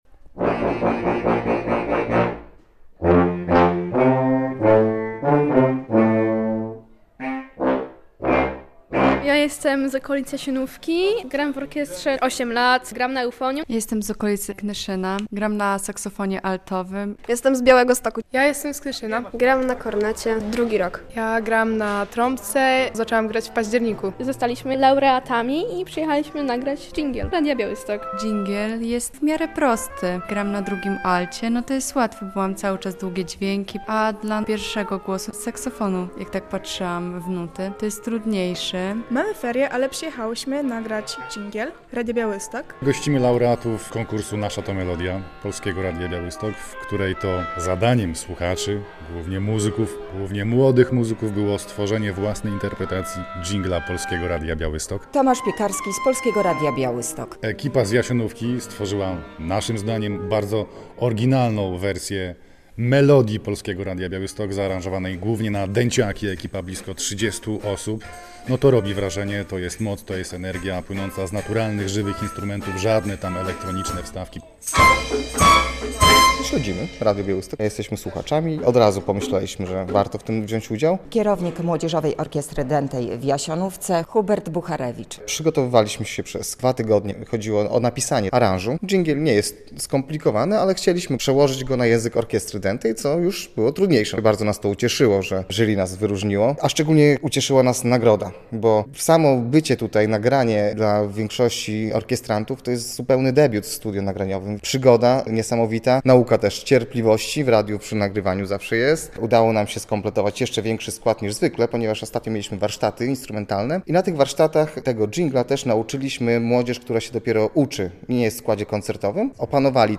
Około 40 muzyków z Młodzieżowej Orkiestry Dętej z Jasionówki odwiedziło Polskie Radio Białystok. W studiu Rembrandt nagrywali swoją wersję naszego radiowego jingla.